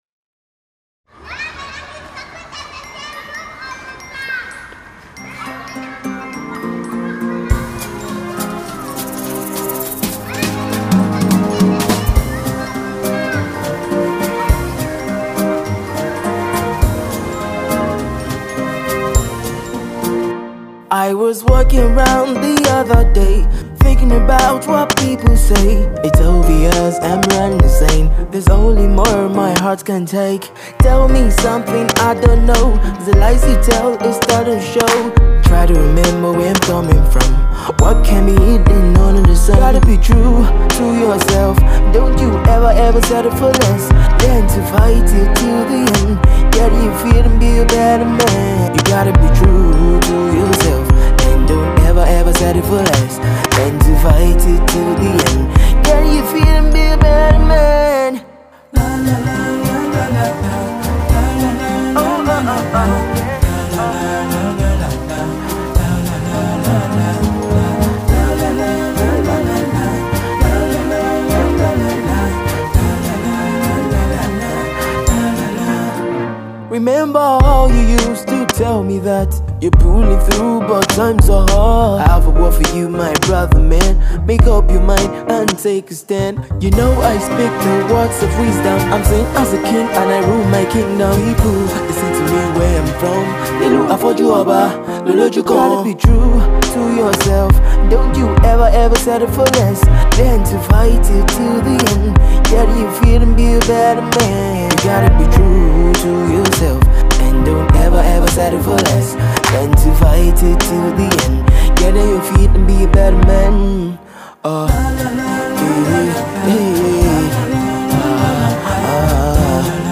laid back acoustic